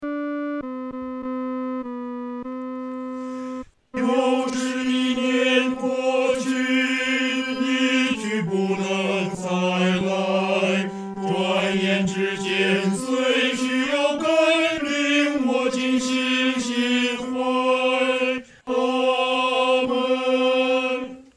合唱
男高
诗班在练习这首诗歌时，要清楚这首诗歌音乐表情是如歌地。